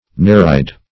Nereid \Ne"re*id\, n.; pl.